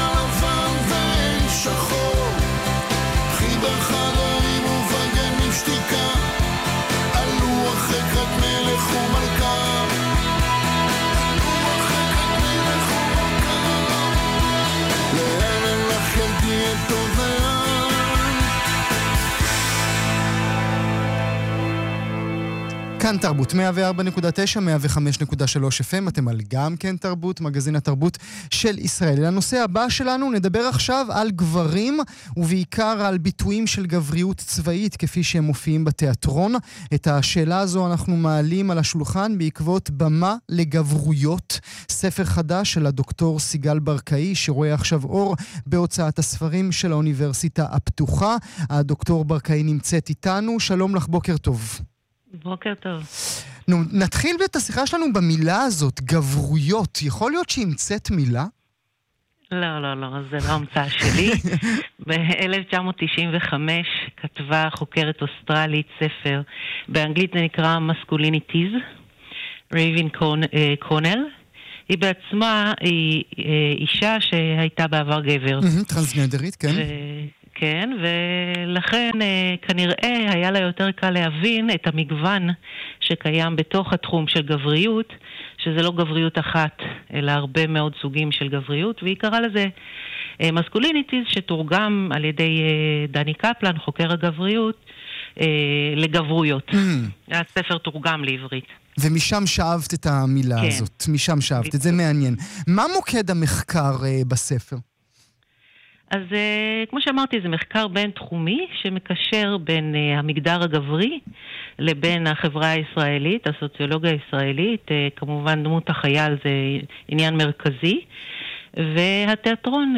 ראיון .mp3